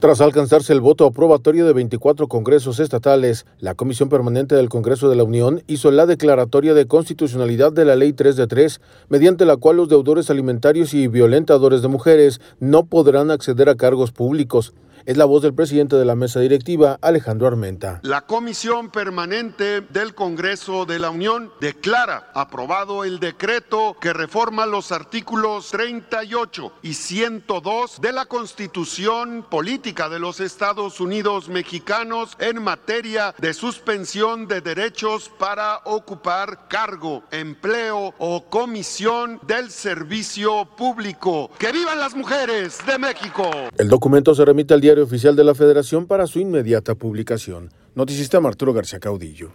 Es la voz del presidente de la Mesa Directiva, Alejandro Armenta: